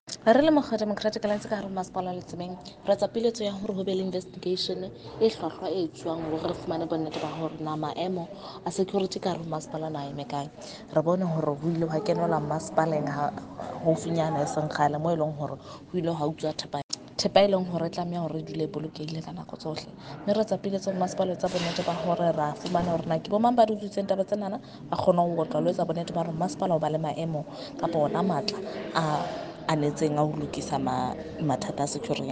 Sesotho by Karabo Khakhau MP.